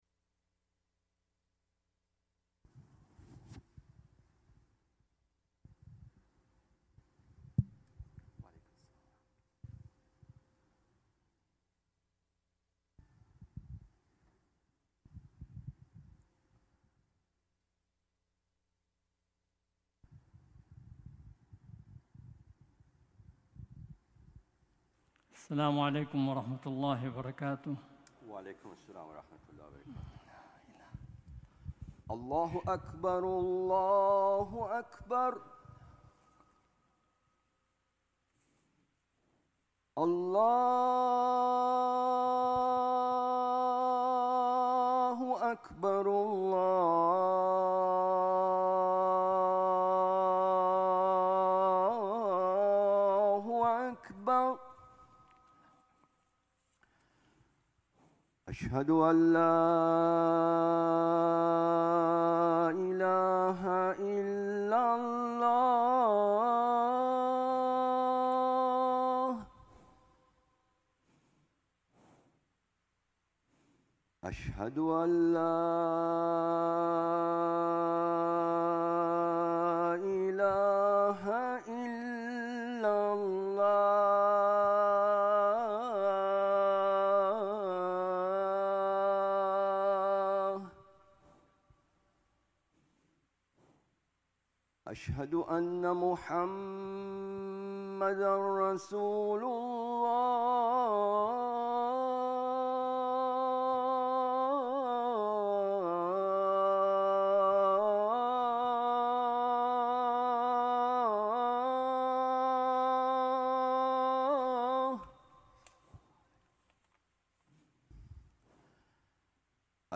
Friday Khutbah - "Hijrah: Some Lessons"